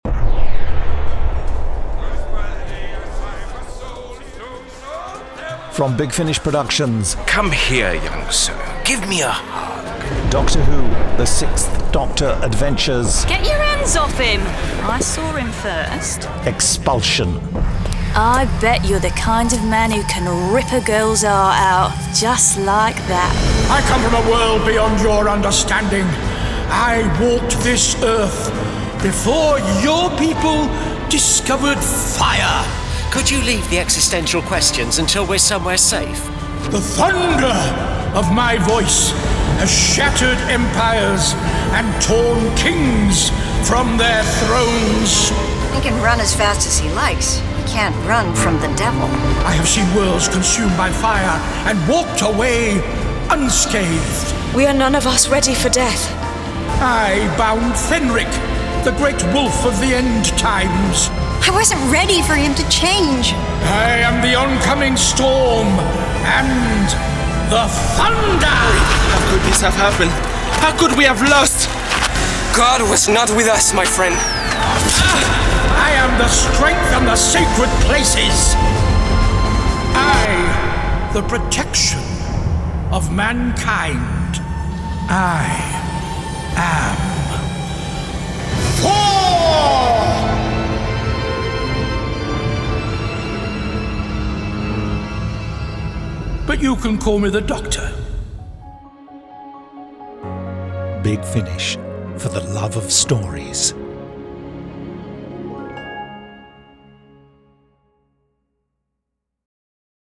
Starring Colin Baker Nicola Bryant